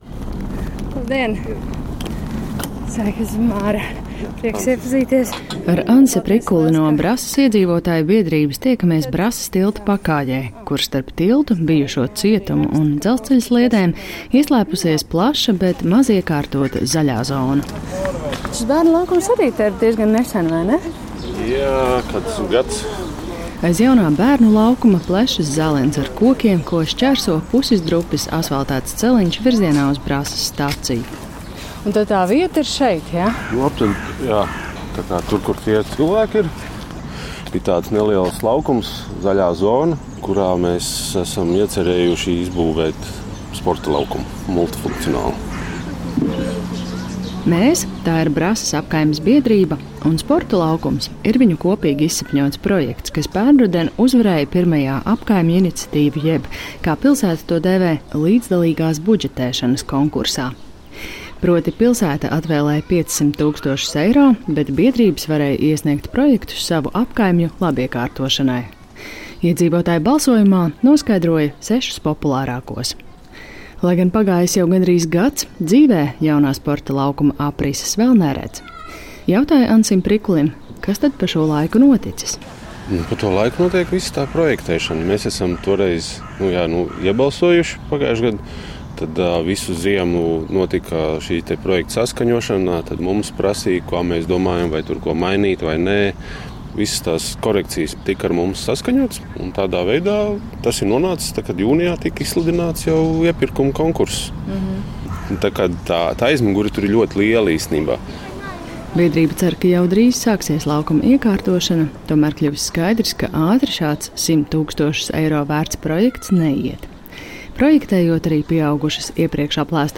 Brasas tilta pakājē